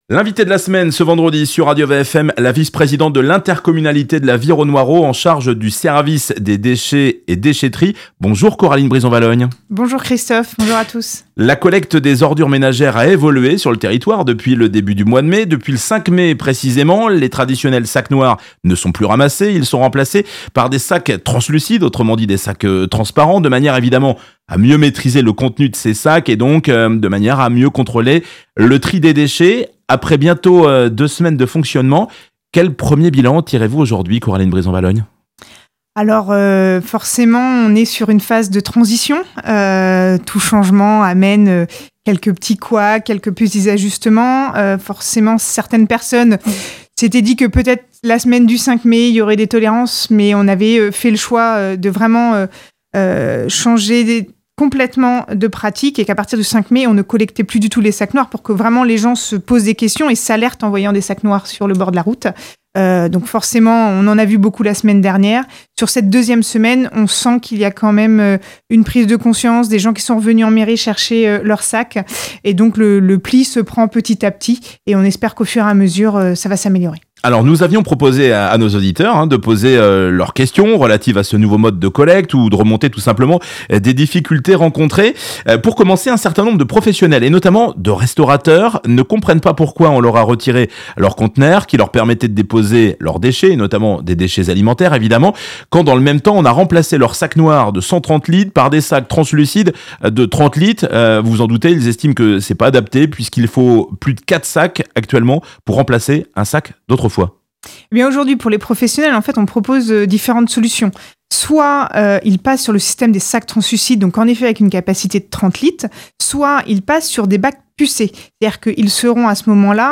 Coraline Brison-Valognes La vice-présidente de l’ Intercom de la Vire au Noireau en charge de la gestion des déchets répond aux questions des auditeurs.